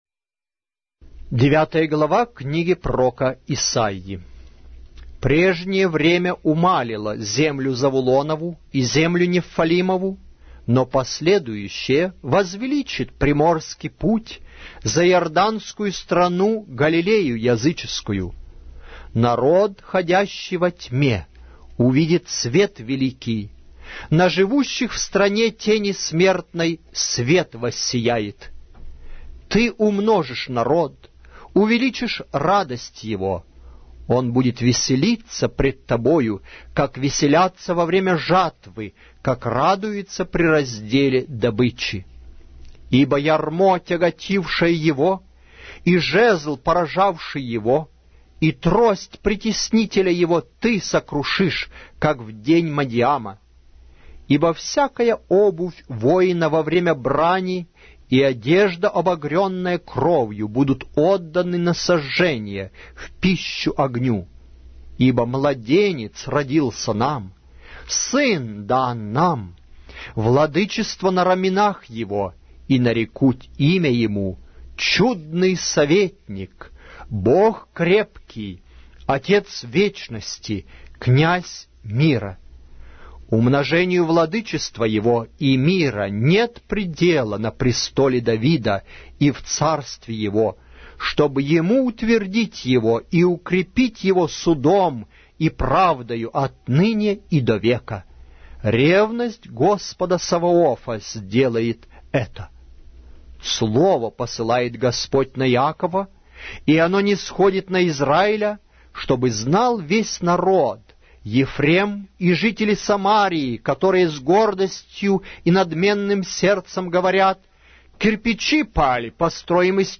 Аудиокнига: Пророк Исаия